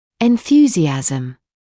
[ɪn’θjuːzɪæzəm][ин’сйуːзиэзэм]энтузиазм, воодушевление; восторг